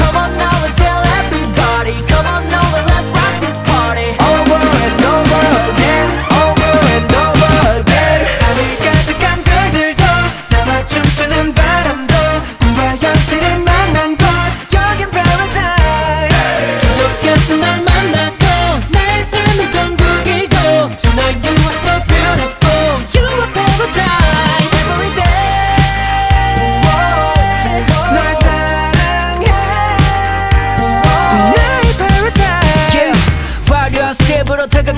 2025-09-01 Жанр: Танцевальные Длительность